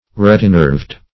Search Result for " retinerved" : The Collaborative International Dictionary of English v.0.48: Retinerved \Ret"i*nerved`\, a. [L. rete a net + E. nerve.] (Bot.) Having reticulated veins.